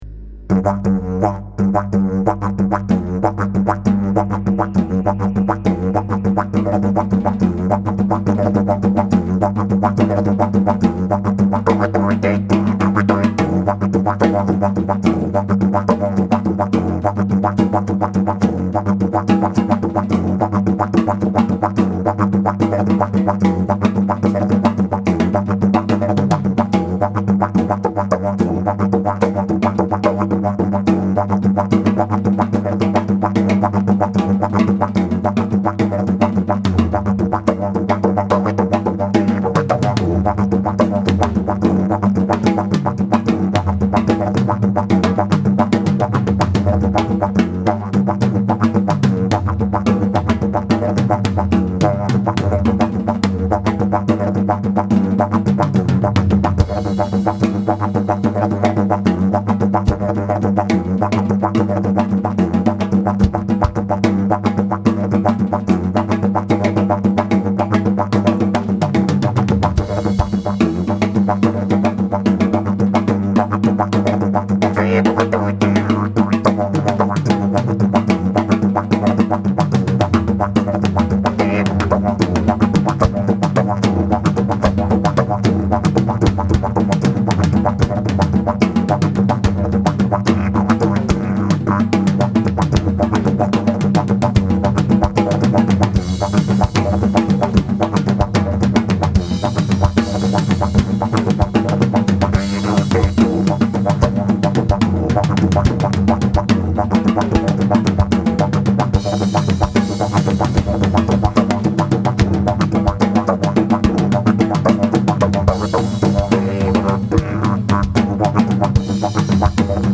Die One Man Band
Drums, Sitar, Didgeridoo